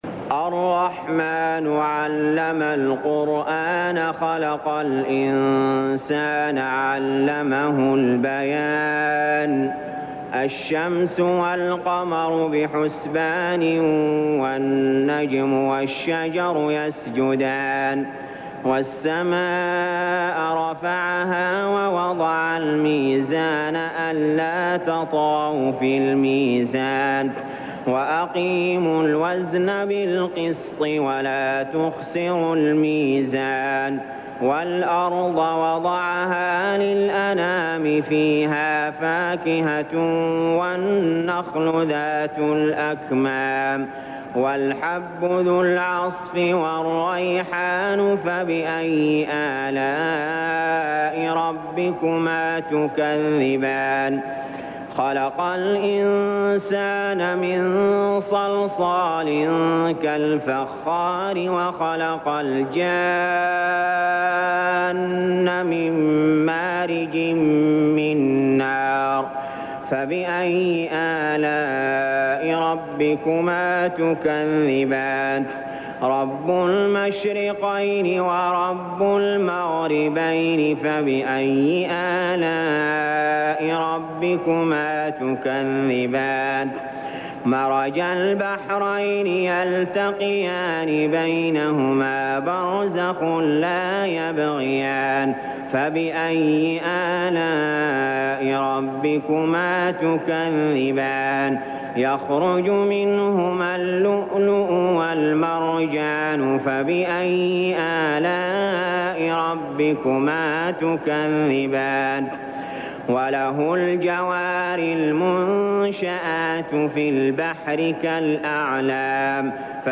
المكان: المسجد الحرام الشيخ: علي جابر رحمه الله علي جابر رحمه الله الرحمن